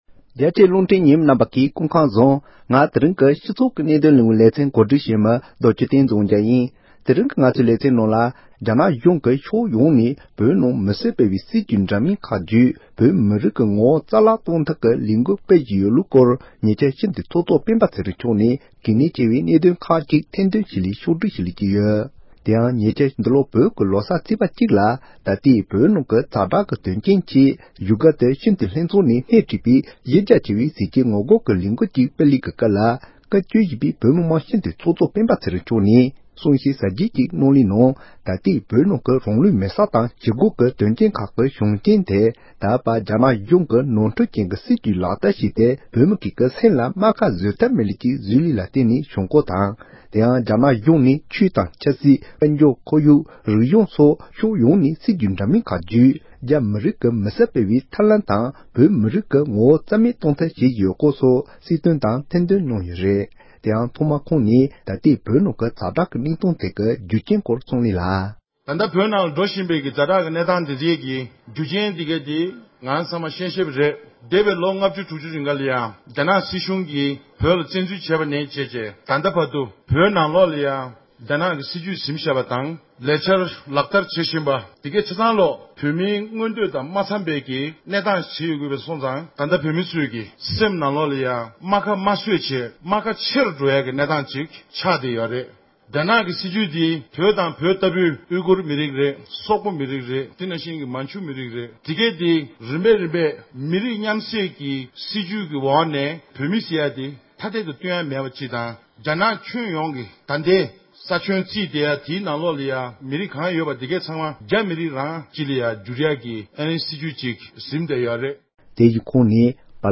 སྤྱི་འཐུས་ཚོགས་གཙོ་མཆོག་གིས་རྒྱ་ནག་གིས་བོད་མིའི་ངོ་བོ་རྩ་བ་ནས་མེད་པ་བཟོ་རྒྱུའི་ལས་འགུལ་སྤེལ་བཞིན་པའི་སྐོར་གསུངས་བ།